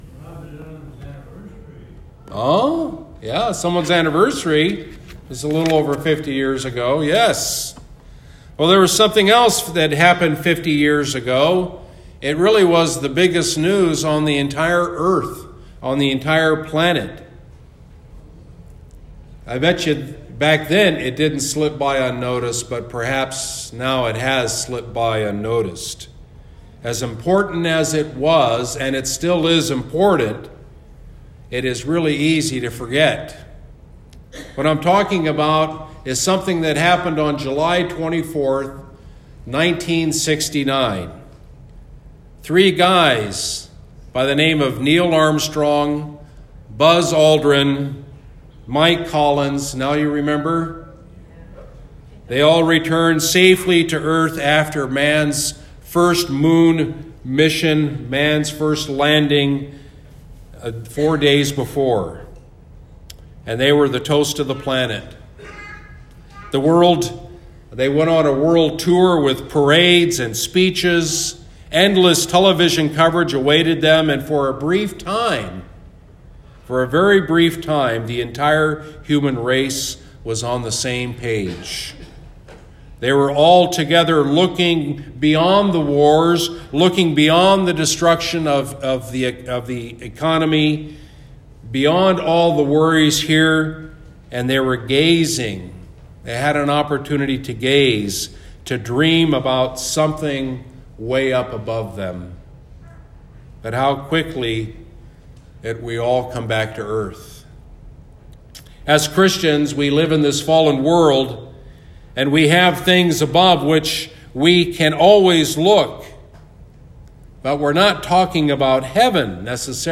Sunday Sermon: “Above–Where Christ Is”